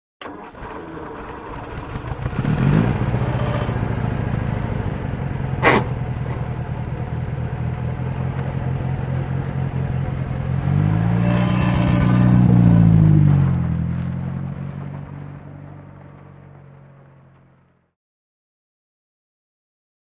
Aston Martin; Start / Away; Slow, Sputtering Start. Grind With Gear Shift, Metallic Vibrations With Away Onto Unpaved Surface. Close To Distant Perspective. Sports Car, Auto.